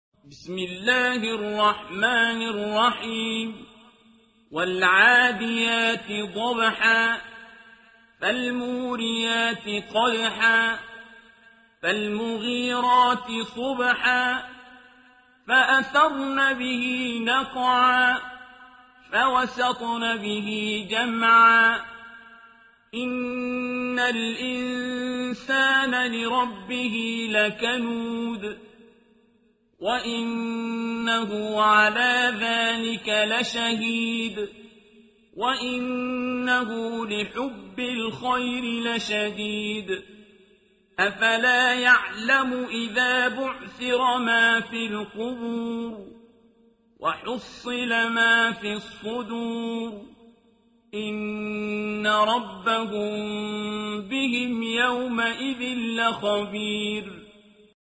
سورة العاديات | تلاوة القرآن عن أرواح الشهداء على طريق القدس